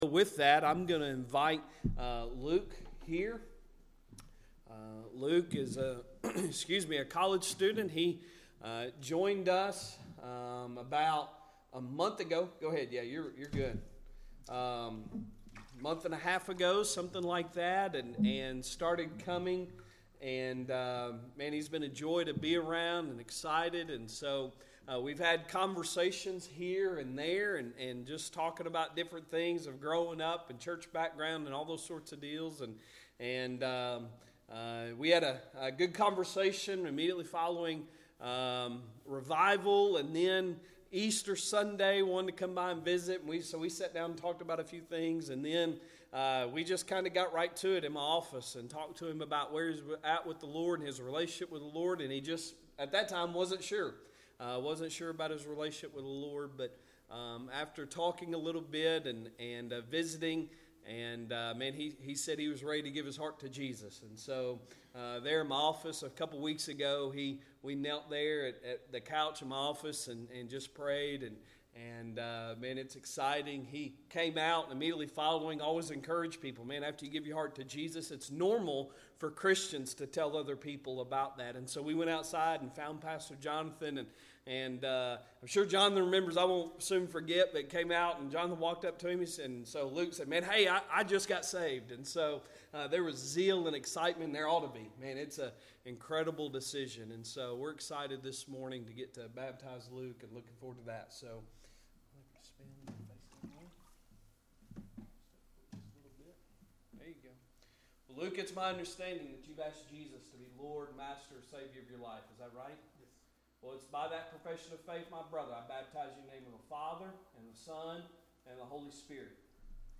Sunday Sermon April 23, 2023